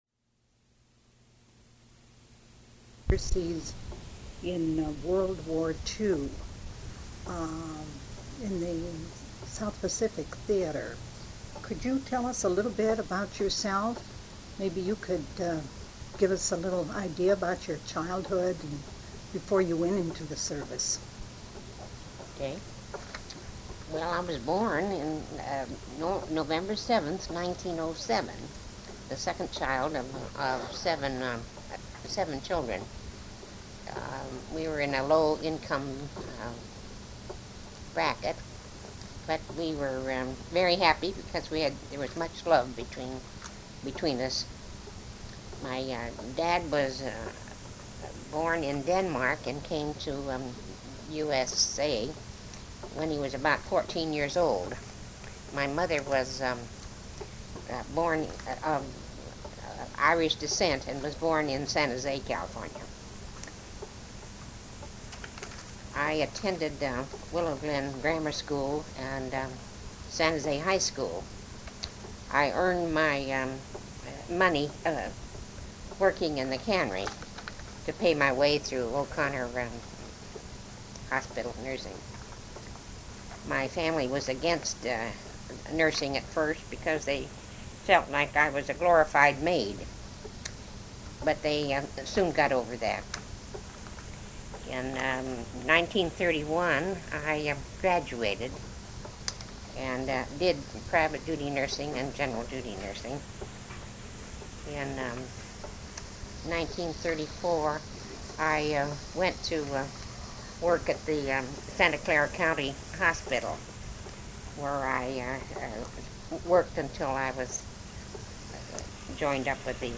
Women's Overseas Service League Oral History Project
Sound recordings Interviews